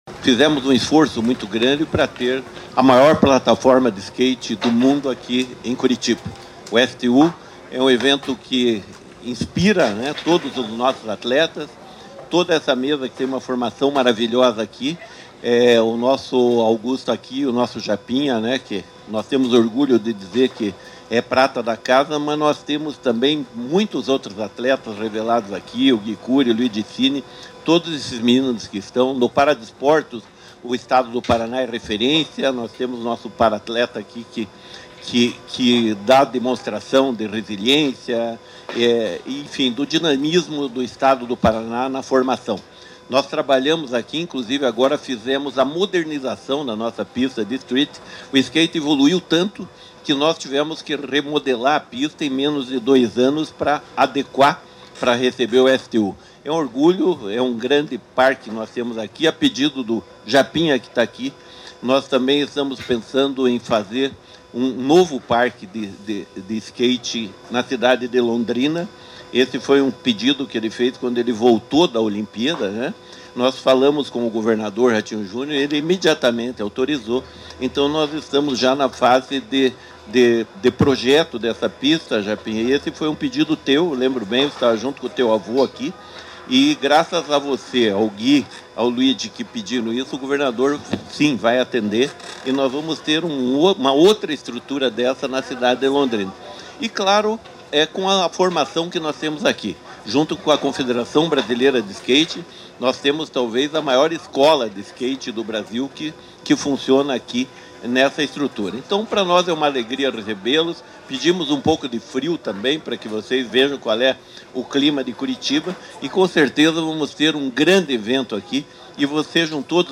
Sonora do secretário do Esporte, Hélio Wirbiski, sobre o STU National 2025